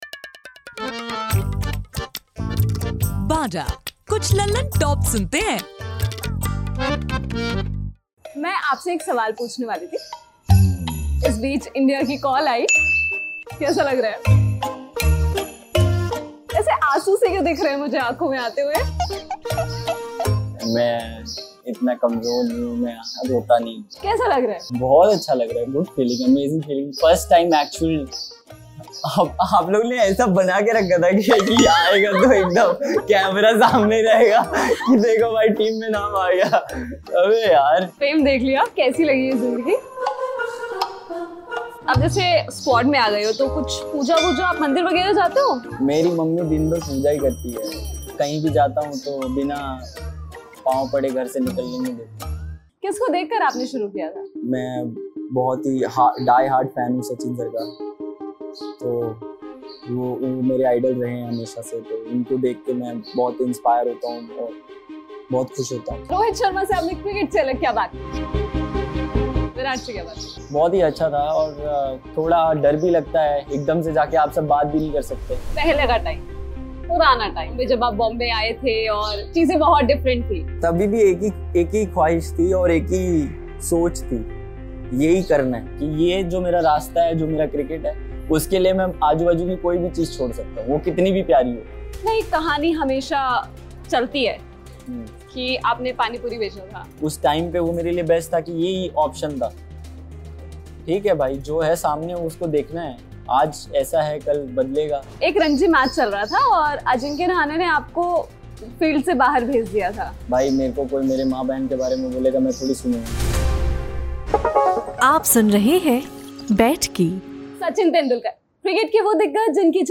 बैठकी के आज के एपिसोड में सुनिए भारतीय क्रिकेट टीम के बैट्समेन यशस्वी जयसवाल को.